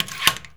door_lock_slide_02.wav